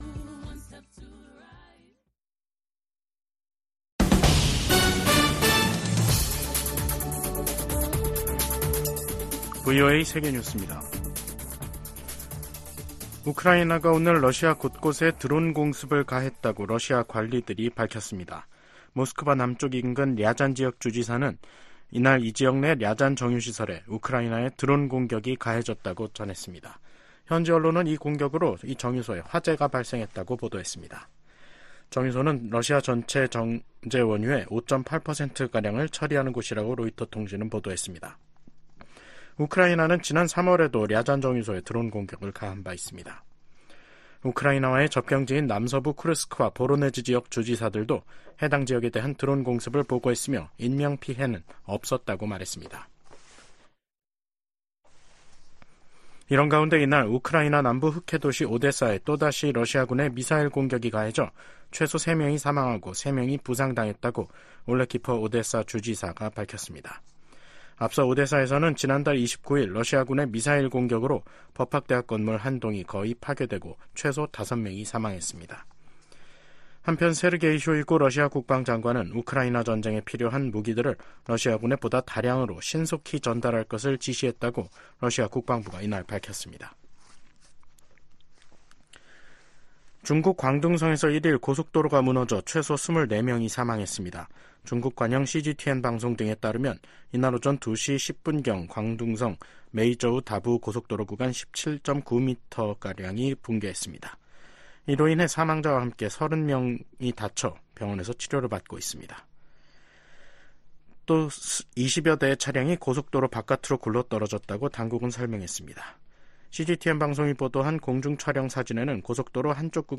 VOA 한국어 간판 뉴스 프로그램 '뉴스 투데이', 2024년 5월 1일 3부 방송입니다.